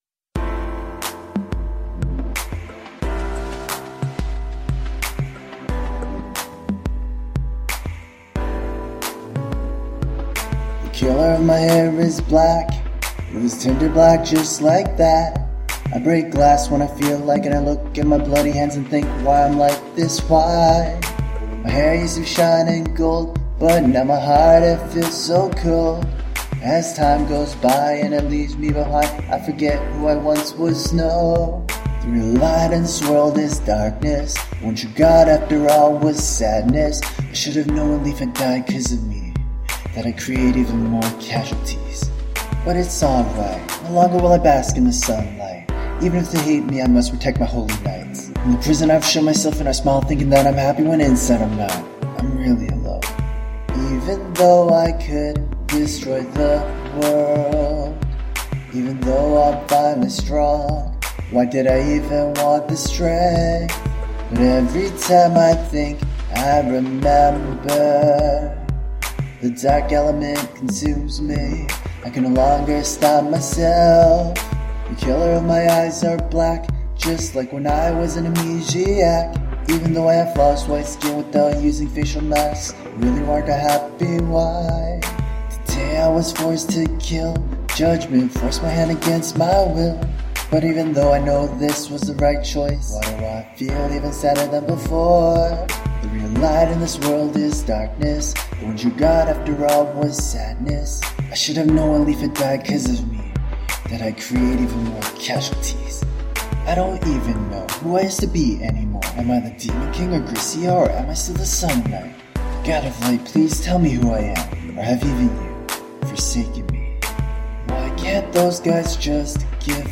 We finally finished singing covers for those songs!
Staff Rendition 1: